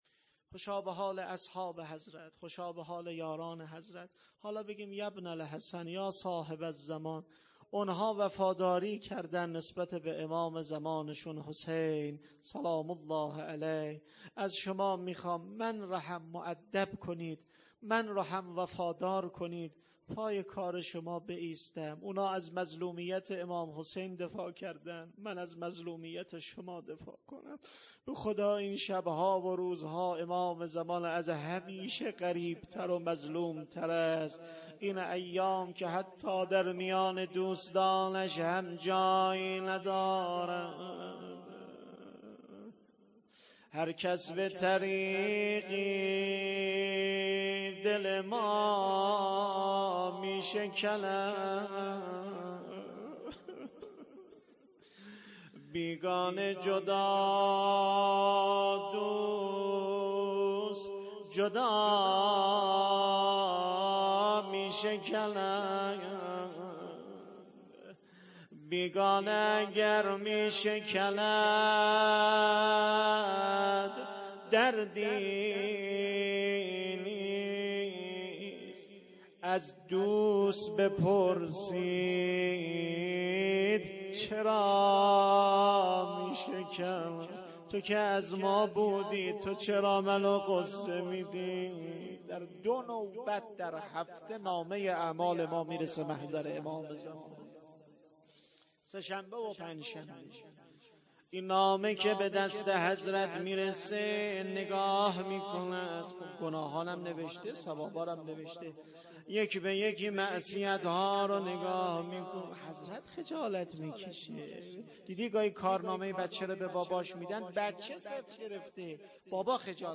هیئت عقیله بنی هاشم سبزوار